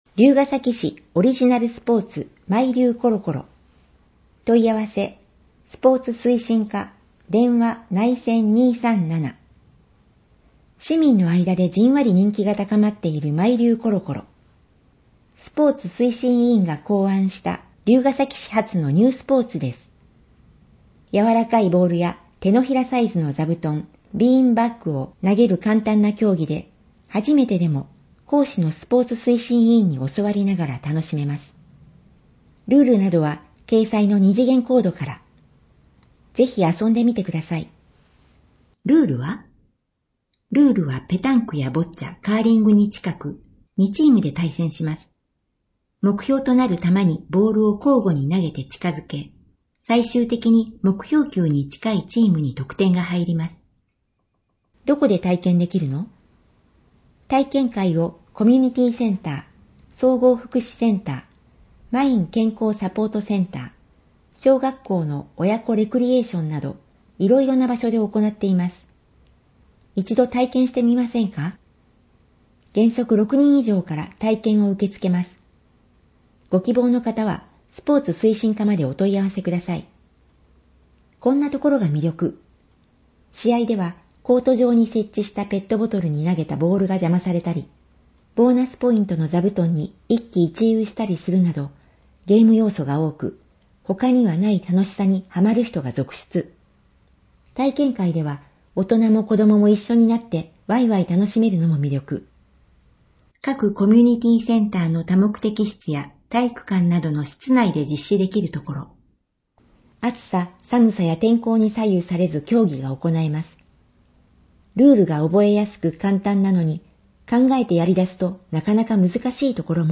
音訳データダウンロード
※MP3データは「龍ケ崎朗読の会」のご協力により作成しています。